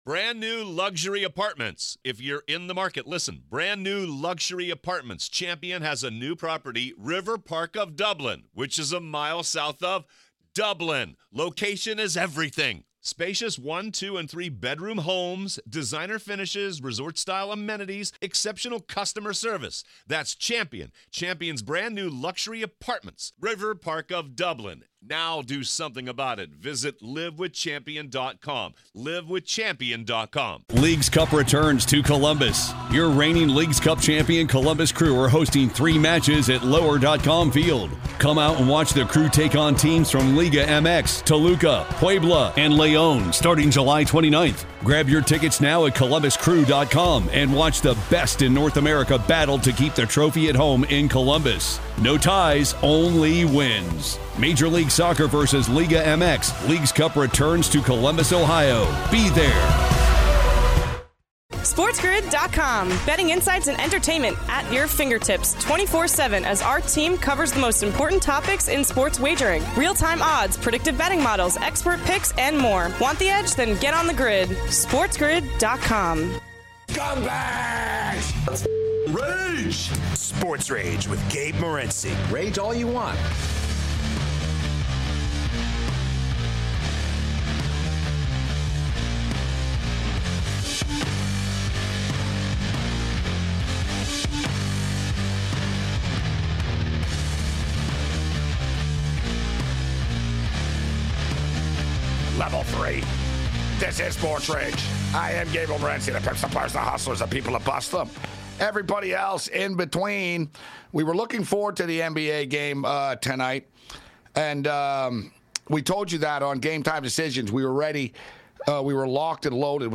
UFC MiddleWeight and former Alabama football national champion, Eryk Anders joins the show to talk about his upcoming bout vs Marc Andre Barriault.